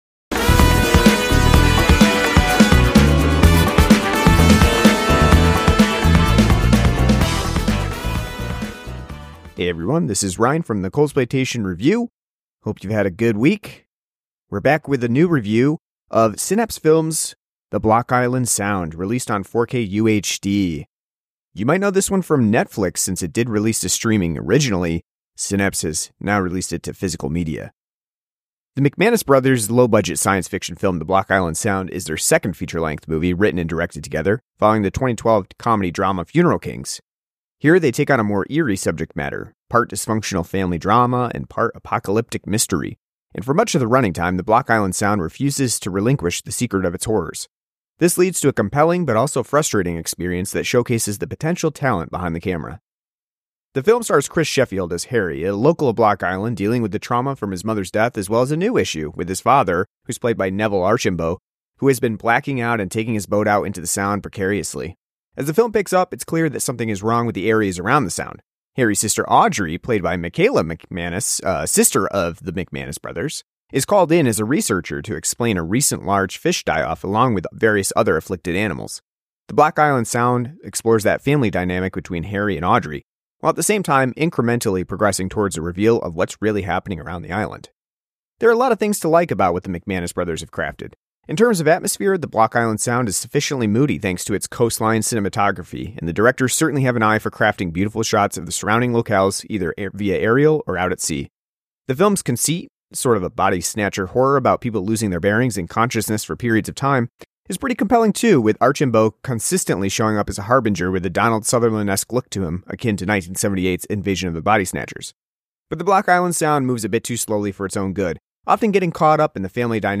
An audio review of The Block Island Sound on 4K UHD from Synapse Films.